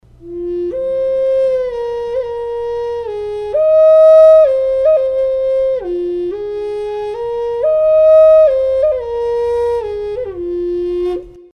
Пимак "Аммонит" Тональность: F#
Пимак или "флейта любви" является национальным духовым инструментом североамериканских индейцев.